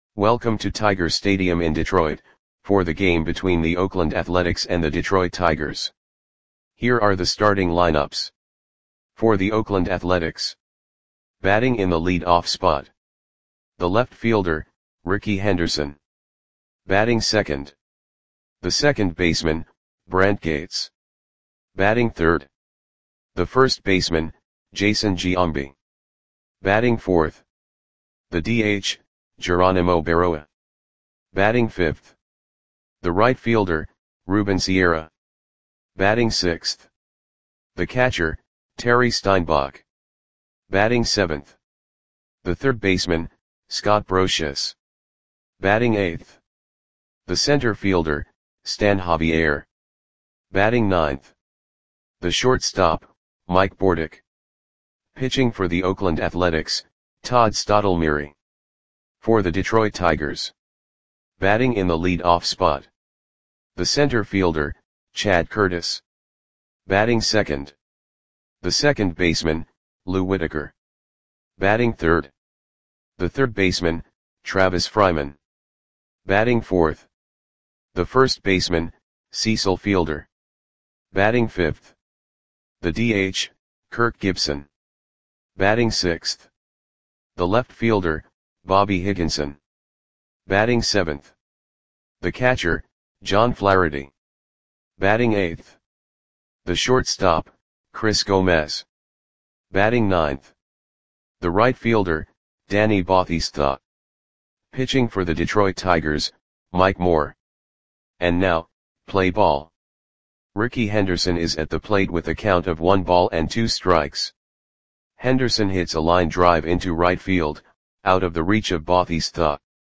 Lineups for the Detroit Tigers versus Oakland Athletics baseball game on July 27, 1995 at Tiger Stadium (Detroit, MI).
Click the button below to listen to the audio play-by-play.